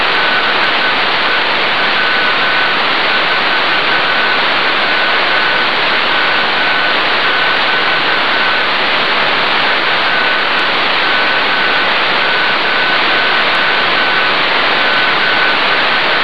403GHz QSO Audio
an excerpt from the 0.5km 403GHz QSO (the file is a 16 second 350kB .WAV file).